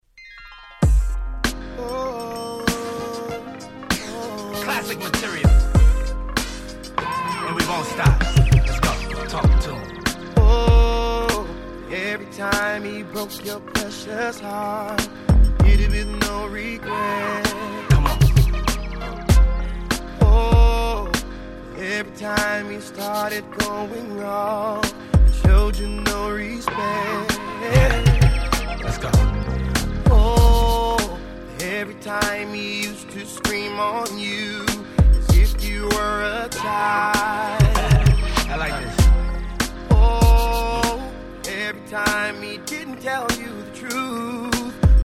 90's感溢れるいなたいHip Hop Soul !!